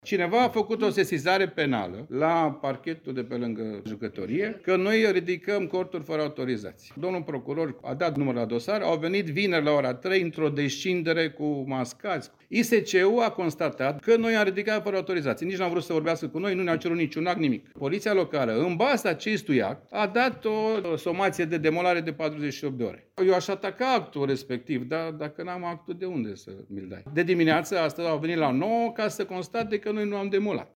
Într-o conferință de presă organizată cu ocazia deschiderii oficiale a sezonului estival de pe litoral